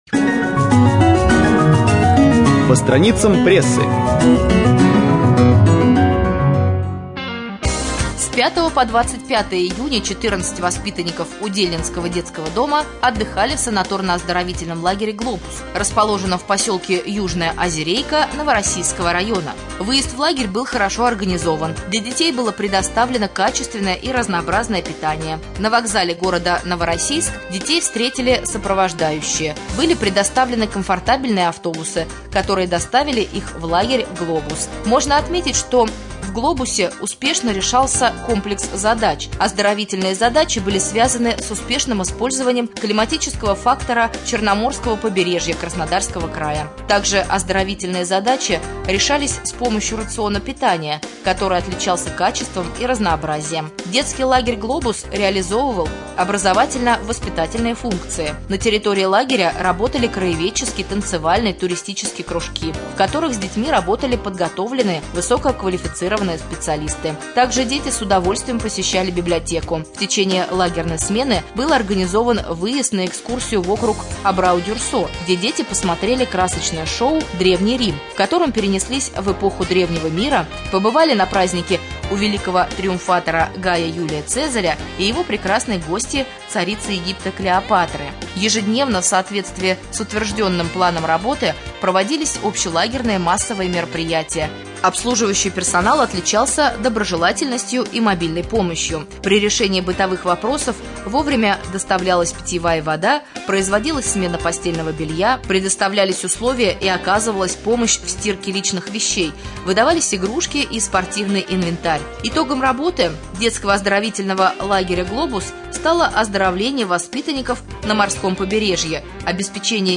22.07.2014 в эфире Раменского радио - РамМедиа - Раменский муниципальный округ - Раменское